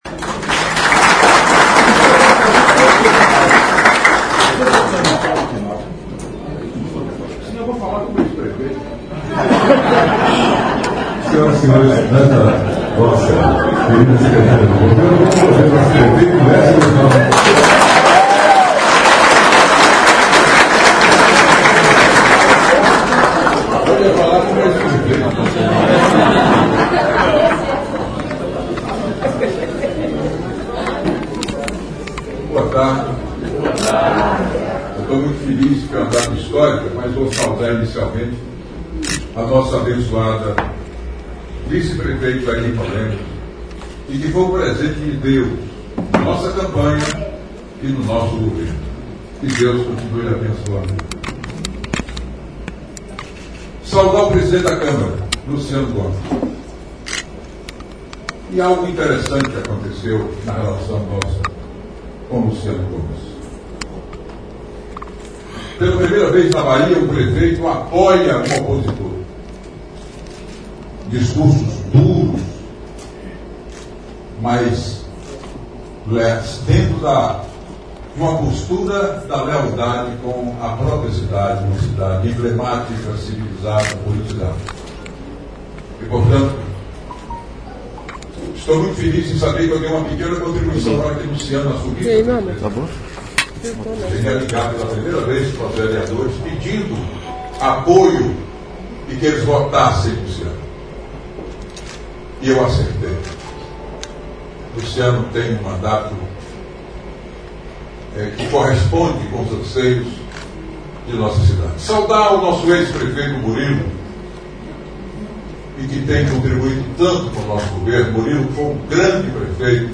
Em entrevista que o BLOG DO ANDERSON exibe no Podcast desta segunda-feira (14), o deputado federal Waldenor Alves Pereira Filho (PT-BA) falou sobre esse assunto que tem gerado polêmica no Parlamento Conquistense.